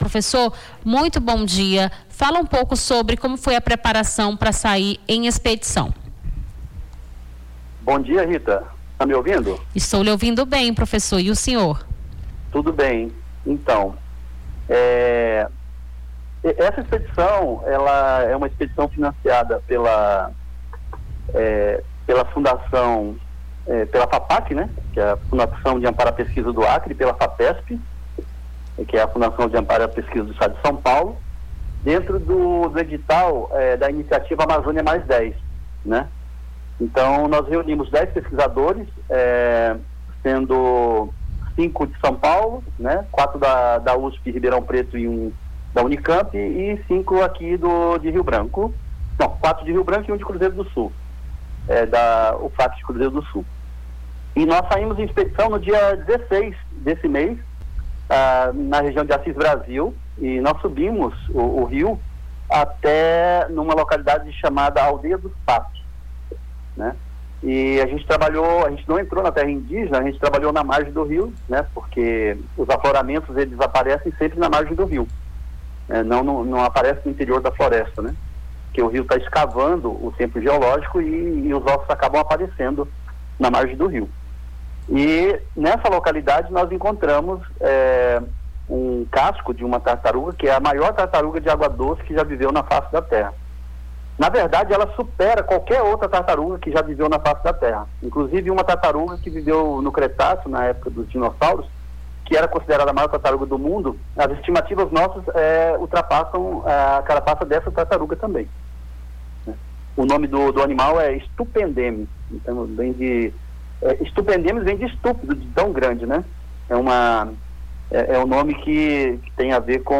Rio Branco